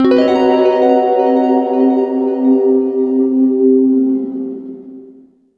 GUITARFX12-R.wav